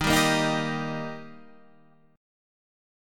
D# chord